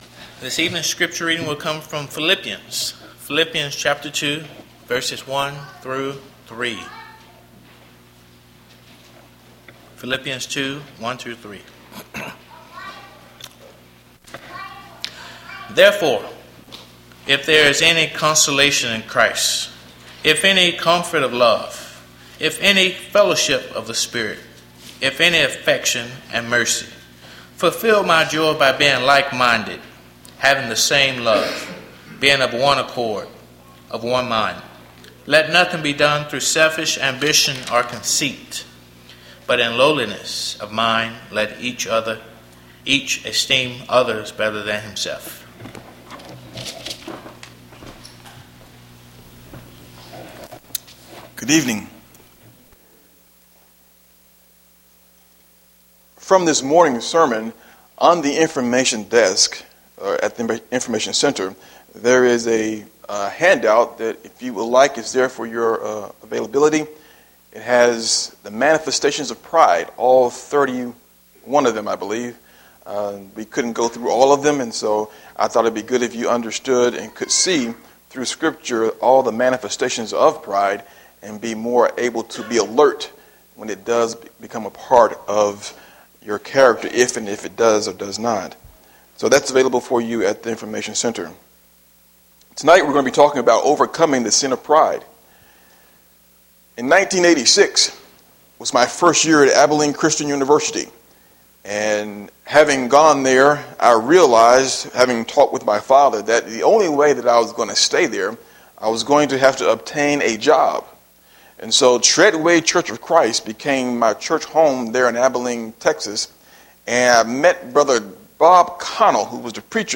PM Worship